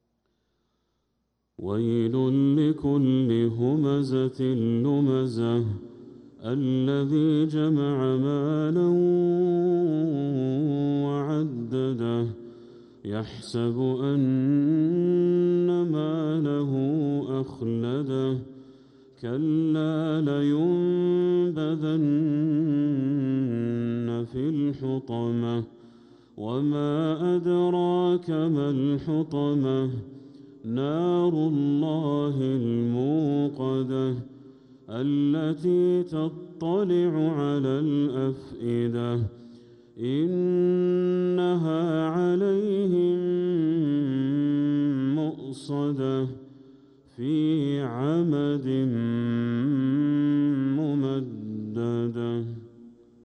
سورة الهمزة كاملة | صفر 1447هـ > السور المكتملة للشيخ بدر التركي من الحرم المكي 🕋 > السور المكتملة 🕋 > المزيد - تلاوات الحرمين